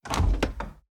Doors Gates and Chests
Chest Close 2.wav